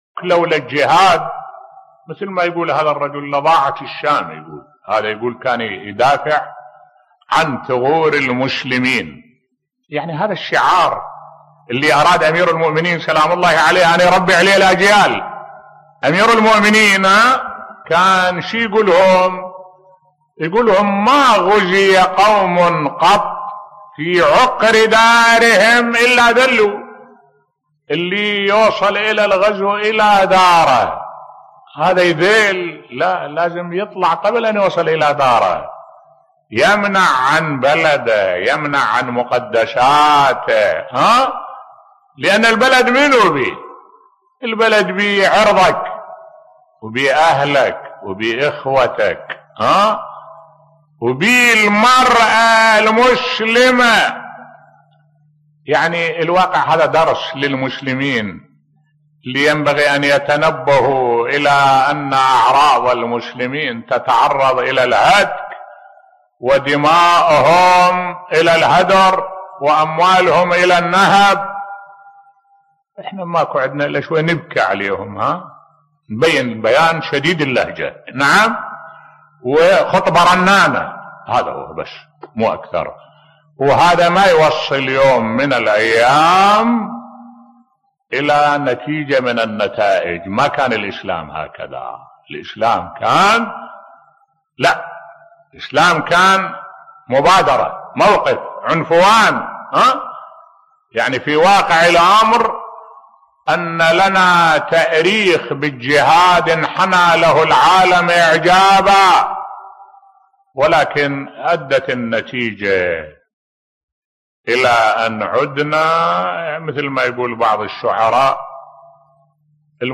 ملف صوتی ما غُزِيَ قوم في عقر دارهم إلا ذلّوا بصوت الشيخ الدكتور أحمد الوائلي